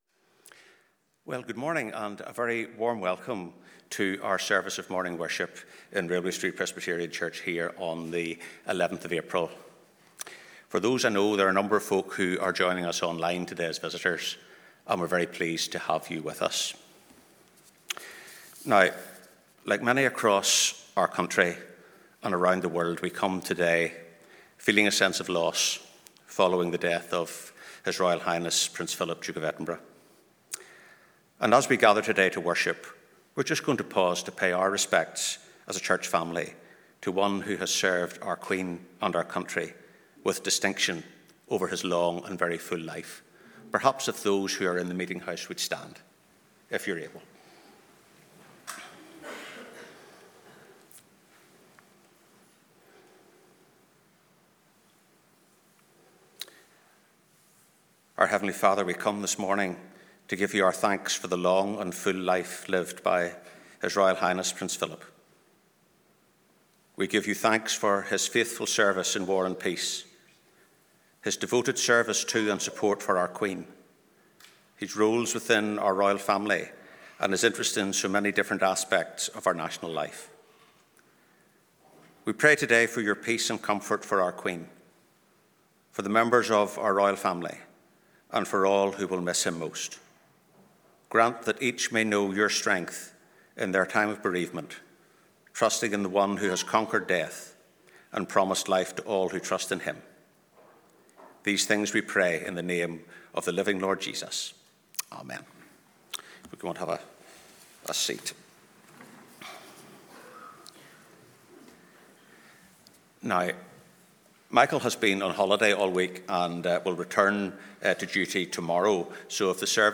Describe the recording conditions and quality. Sunday 11th April 2021 Live @ 10:30am Morning Service